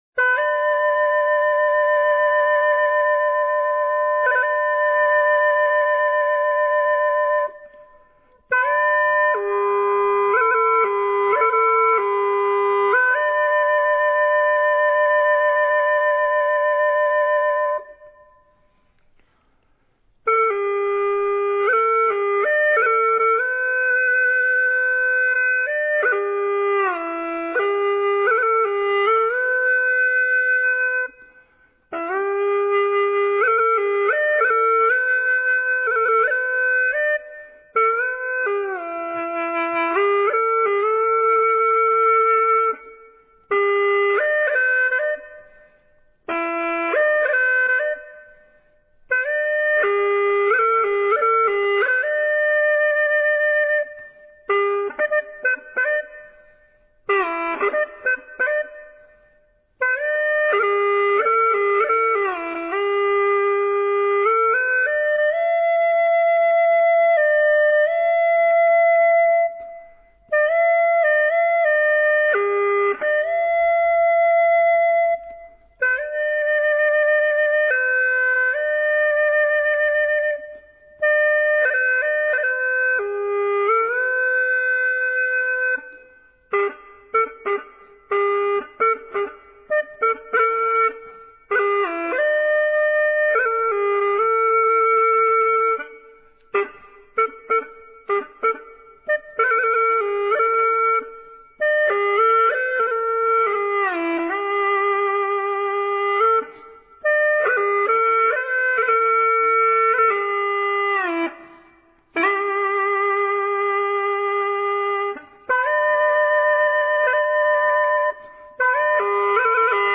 佛音 冥想 佛教音乐 返回列表 上一篇： 落叶满天--《静水禅音》